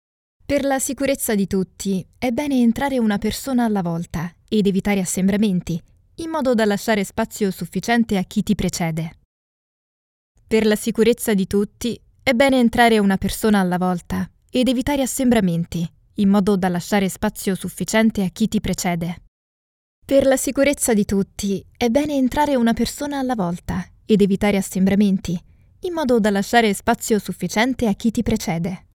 Female
Safety Video
Words that describe my voice are Engage, Natural, Energic.
All our voice actors have professional broadcast quality recording studios.